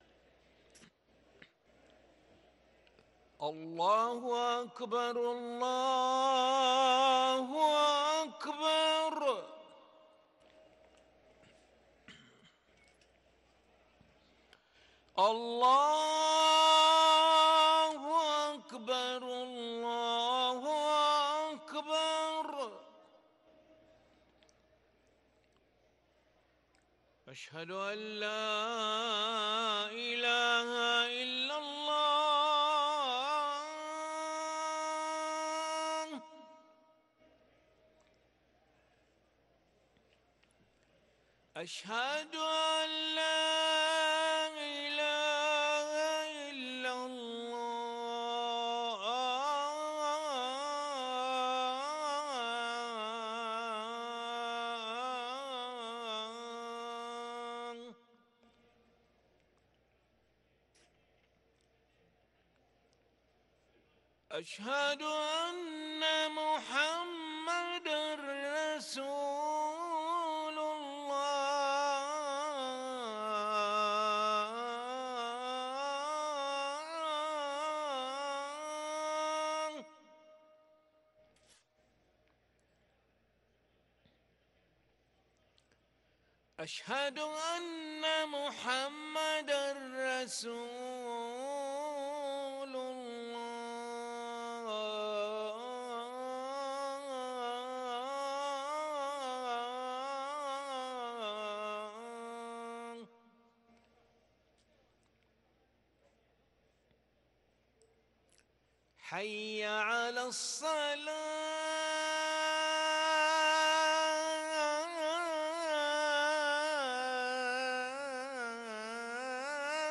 أذان العشاء للمؤذن علي ملا الخميس 28 جمادى الأولى 1444هـ > ١٤٤٤ 🕋 > ركن الأذان 🕋 > المزيد - تلاوات الحرمين